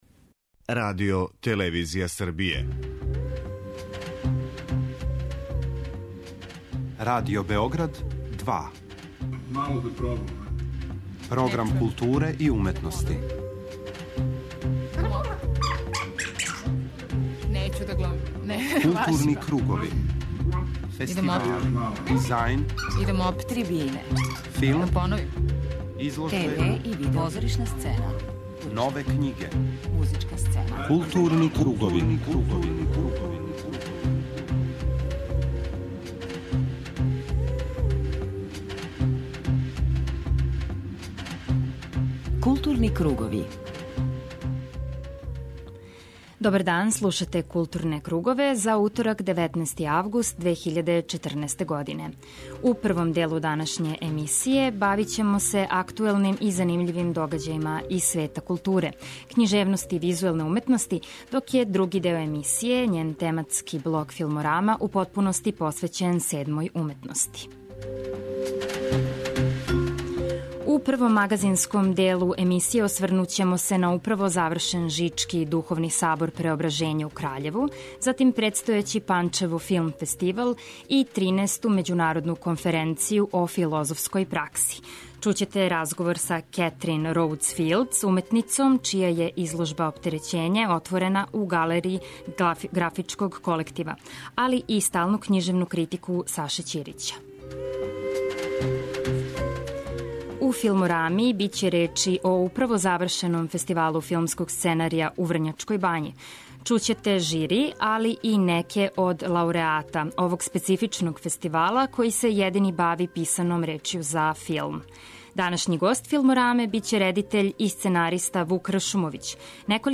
Чућете утиске учесника фестивала, филмских аутора и лауреата.